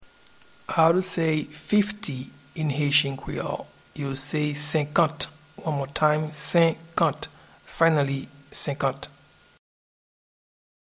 Pronunciation and Transcript:
Fifty-in-Haitian-Creole-Senkant.mp3